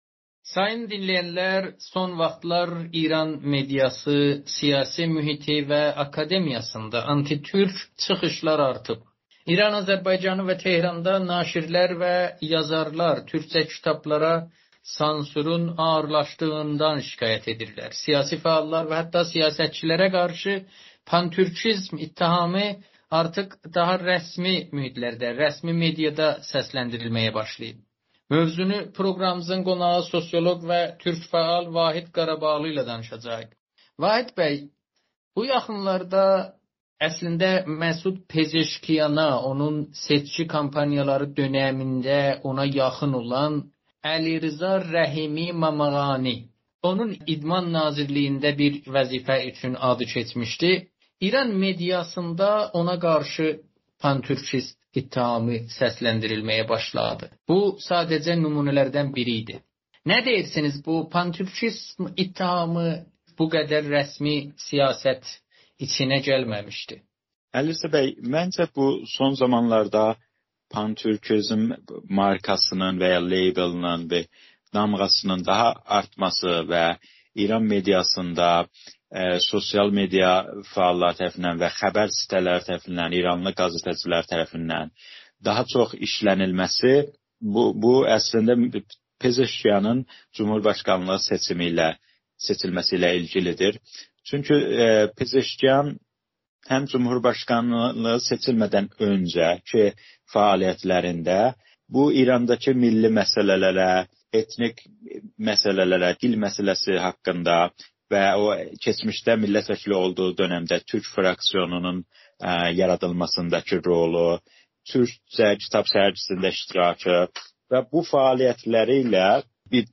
Amerikanın Səsinə müsahibədə son vaxtlar İranın siyasi mühitində tez-tez səsləndirilən pan-türkizm ifadəsi haqqında danışıb.